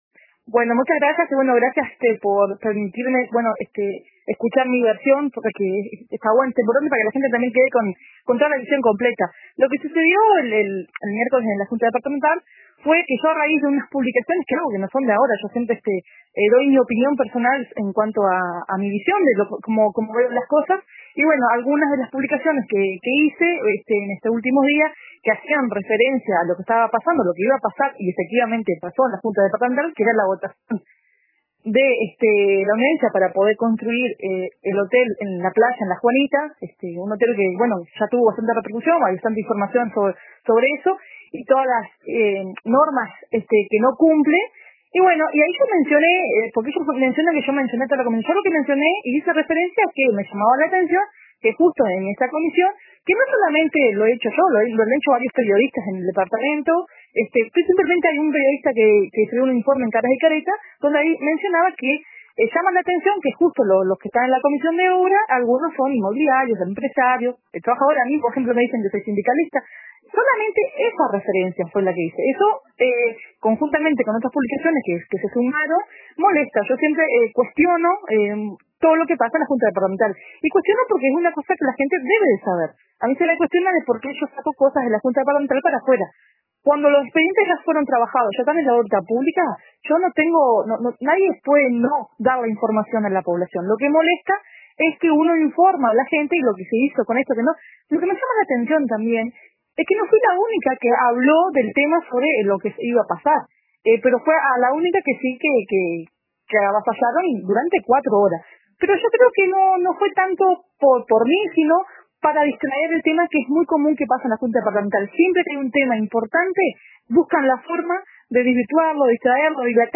La misma edila Ana Antúnez se comunicó con RADIO RBC para decir que ella es la autora de esas publicaciones, pero que se habla de lo que se interpreta, no de lo que verdaderamente escribió en sus redes sociales…